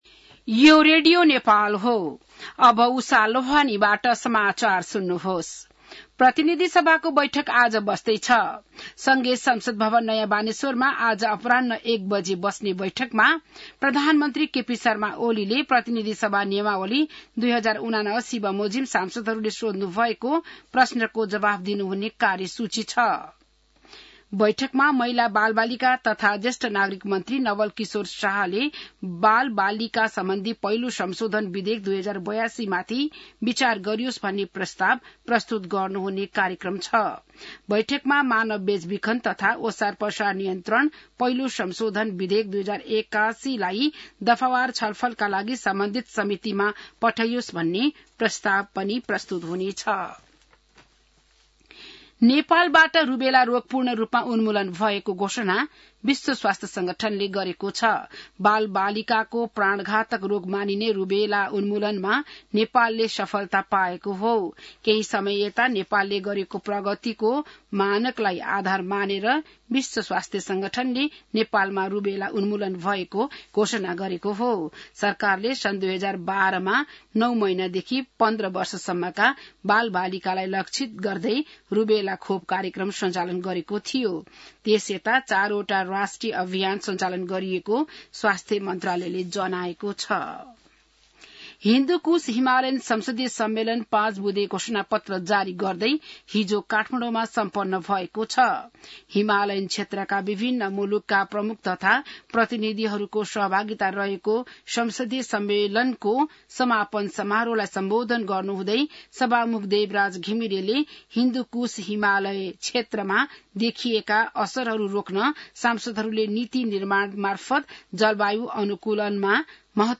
बिहान १० बजेको नेपाली समाचार : ४ भदौ , २०८२